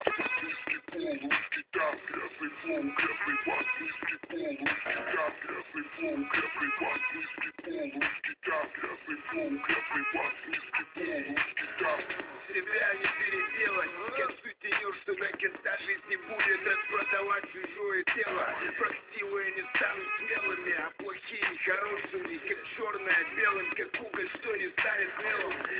В общем музончик такой для саба) репчик.
грязный флоу, грязный бас низкий пол русский таз.
вот короче записал на свой супер диктофон*